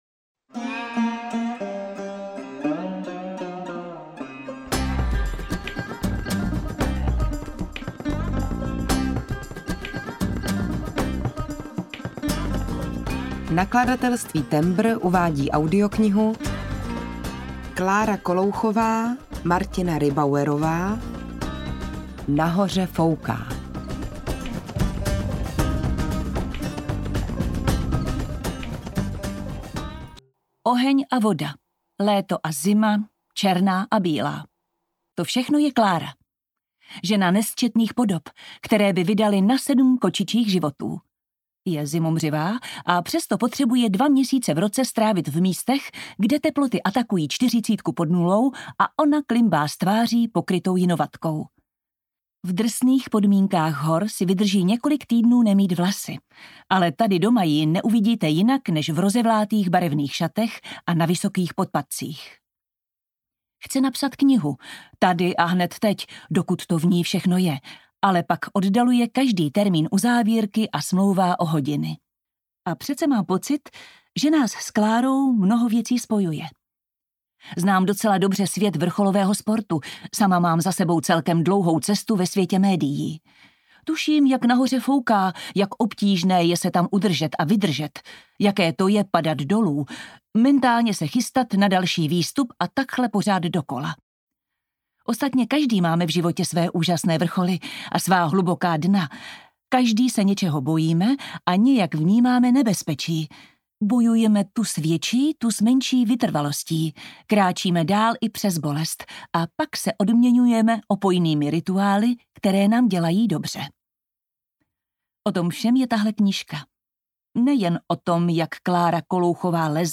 Nahoře fouká audiokniha
Ukázka z knihy